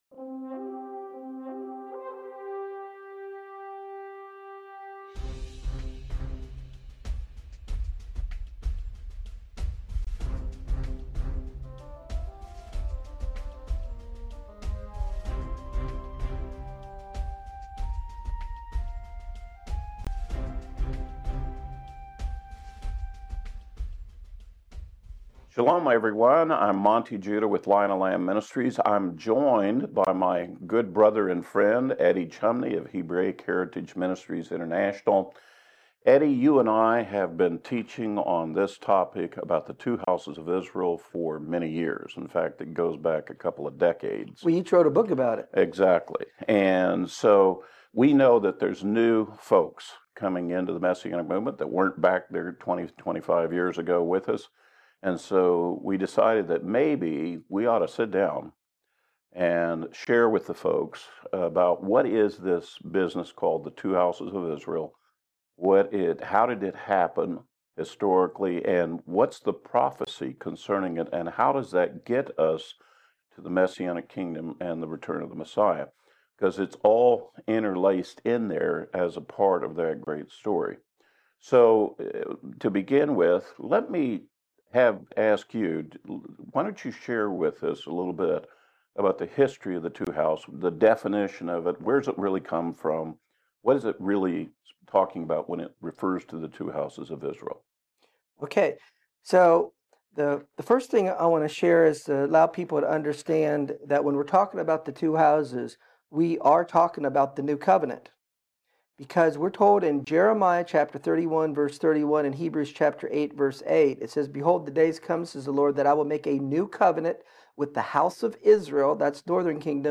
This comprehensive teaching examines who these houses are, their historical division, and prophetic significance.